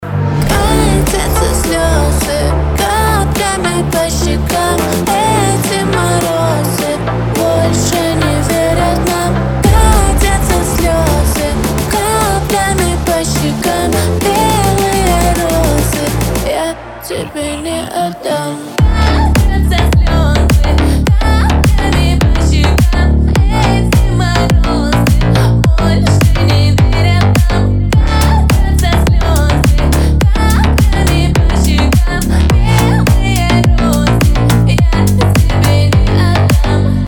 грустные